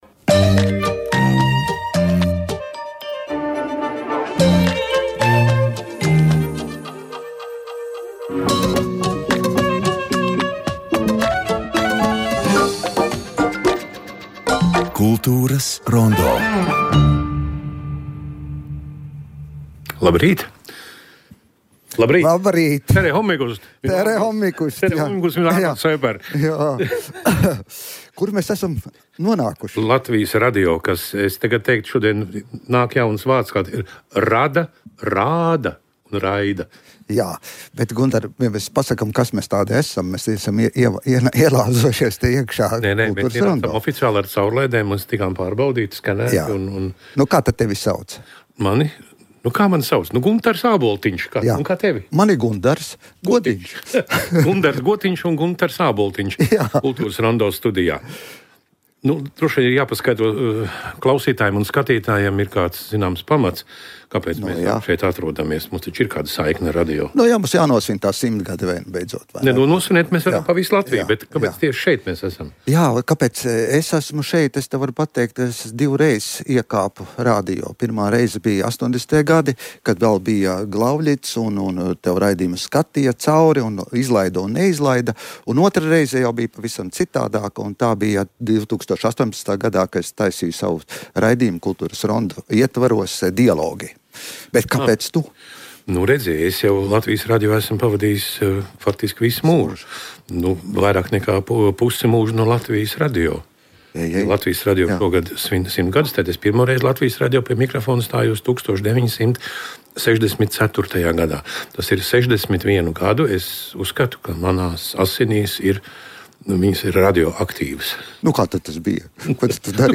"Kultūras rondo" ir kvalitatīvākais un daudzpusīgākais radio raidījums par kultūras procesiem Latvijā un pasaulē, kas sniedz arī izvērstas anotācijas par aktuāliem notikumiem mūzikā, mākslā, kino, teātrī, literatūrā, arhitektūrā, dizainā u.c. "Kultūras rondo" redzes lokā ir tā kultūrtelpa, kurā pašreiz dzīvojam. Mēs ne tikai palīdzam orientēties kultūras notikumos, bet tiešraides sarunās apspriežam kultūras notikumu un kultūras personību rosinātas idejas.